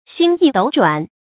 星移斗转 xīng yí dǒu zhuǎn
星移斗转发音
成语正音斗，不能读作“dòu”；转，不能读作“zhuàn”。